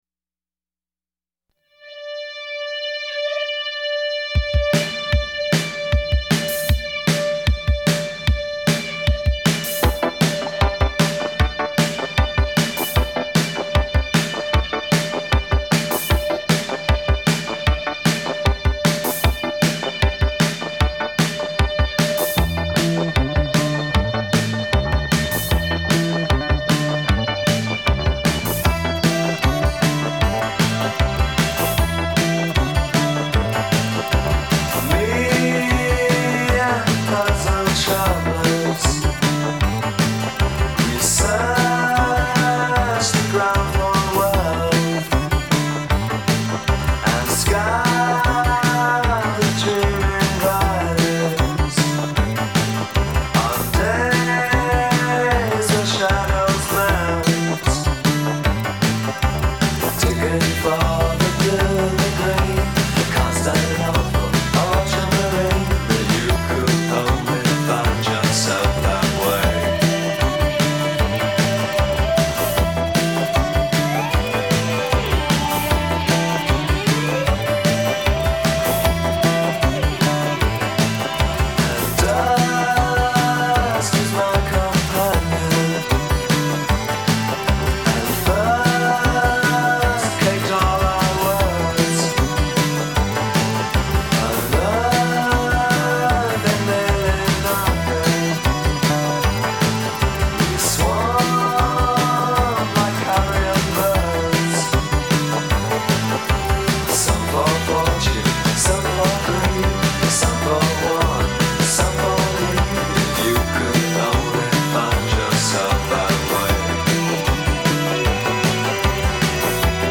synth/prog/goth-leaning rockers
12-string guitar